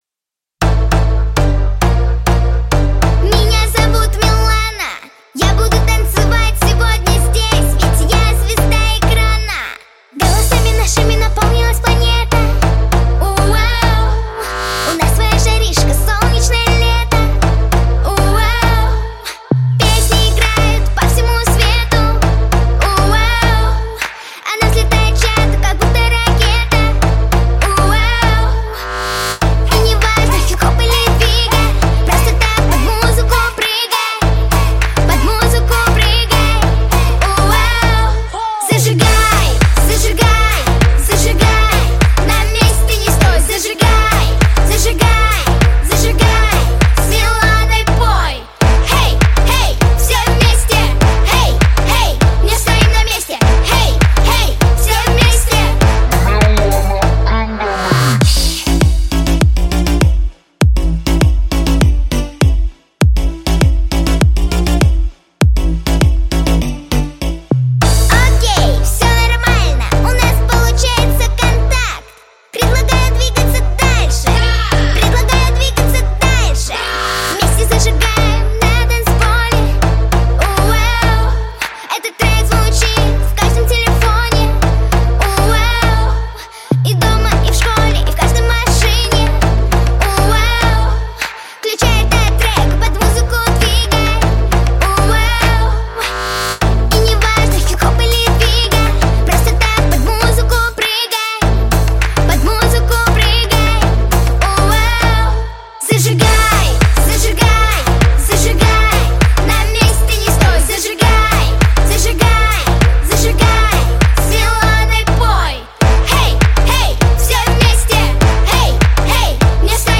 • Категория: Детские песни
танцы, детская дискотека